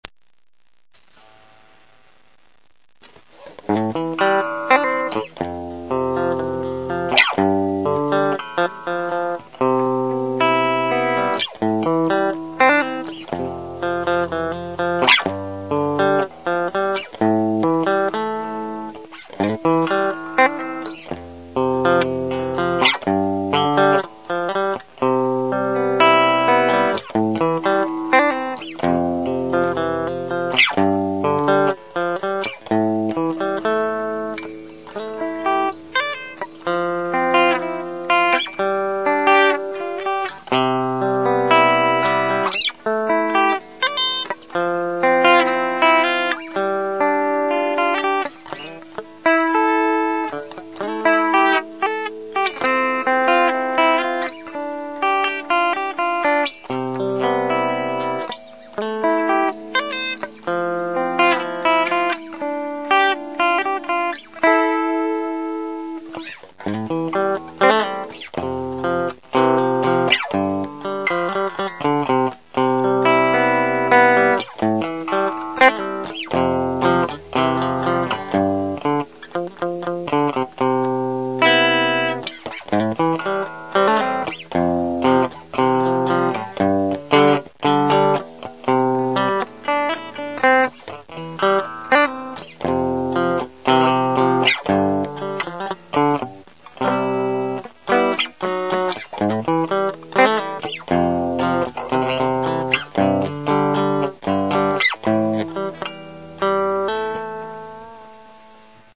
ただのコード弾きですけど、一応メロディーつけてみたけどなんかしっくりこないっす。（笑